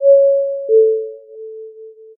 ping.ogg